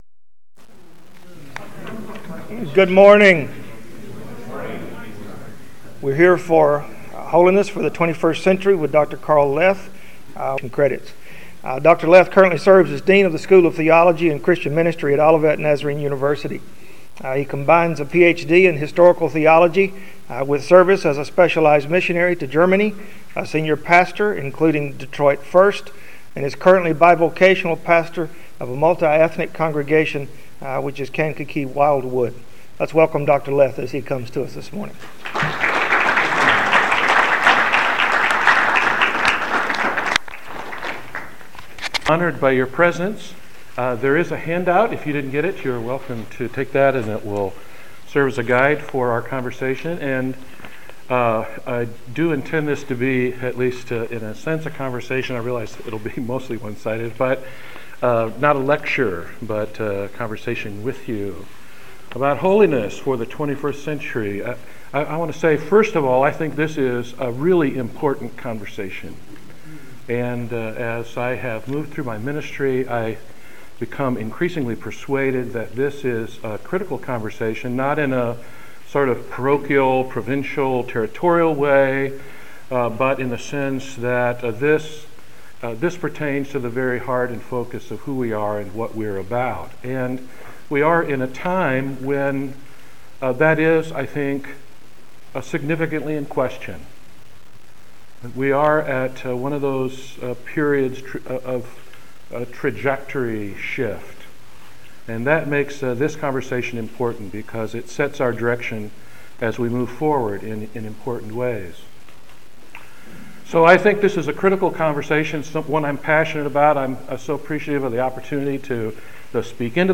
General Assembly 2013: Holiness for the 21st Century